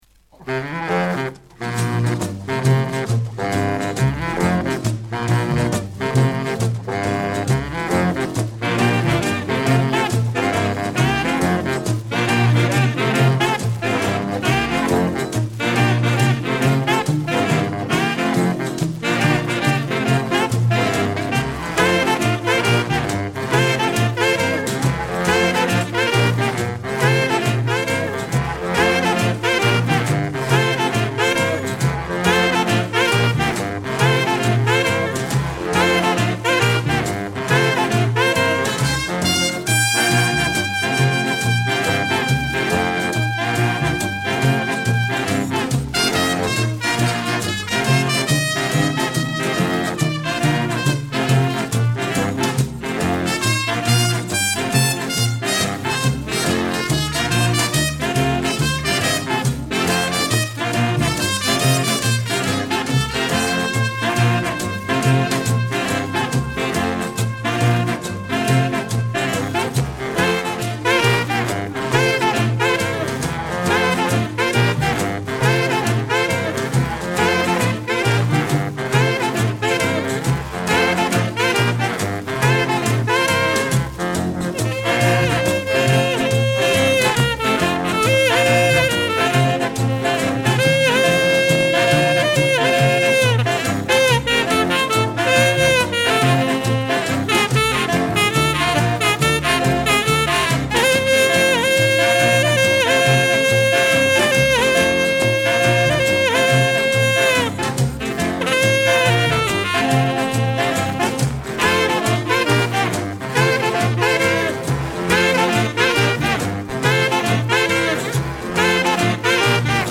recorded 1962
sax